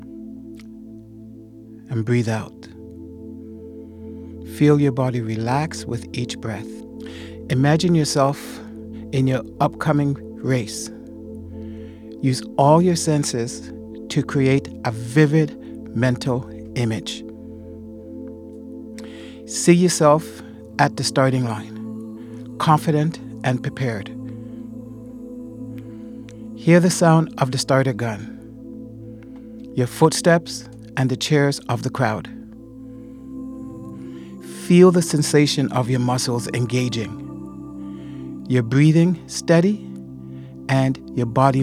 The Meditation Version
Designed for driven athletes, this guided session enhances recovery, visualization, and discipline while helping the body fully reset.